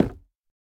Minecraft Version Minecraft Version 1.21.5 Latest Release | Latest Snapshot 1.21.5 / assets / minecraft / sounds / block / chiseled_bookshelf / break6.ogg Compare With Compare With Latest Release | Latest Snapshot
break6.ogg